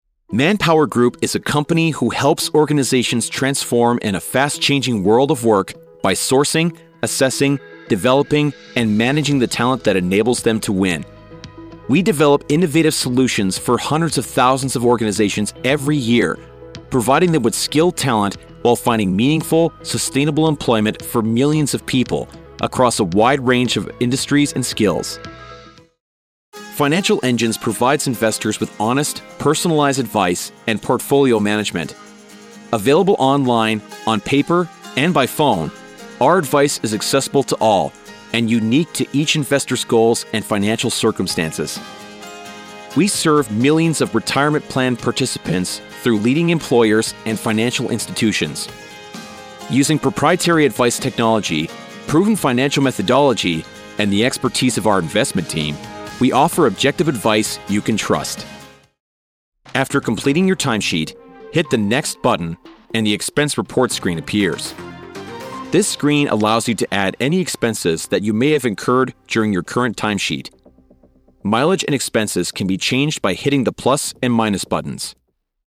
Professional Corporate Voice Over Talent | The Voice Realm
0916Corporate_Demo.mp3